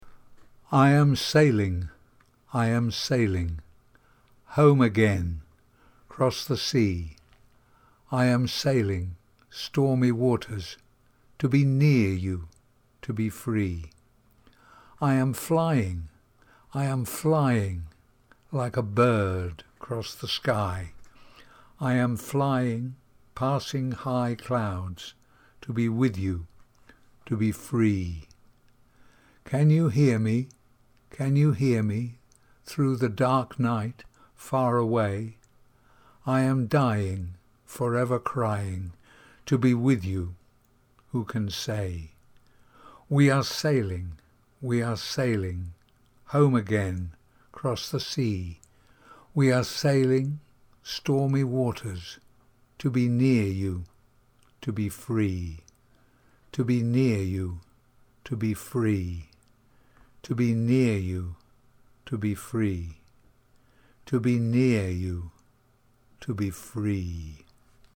Fichiers de prononciation
Sailing Pronunciation.mp3